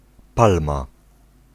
Ääntäminen
Synonyymit arécacée palmito Ääntäminen France: IPA: [ɛ̃ pal.mje] Tuntematon aksentti: IPA: /pal.mje/ Haettu sana löytyi näillä lähdekielillä: ranska Käännös Ääninäyte Substantiivit 1. palma {f} Suku: m .